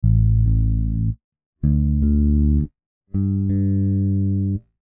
Звуки бас-гитары
Настройка тона басов гитары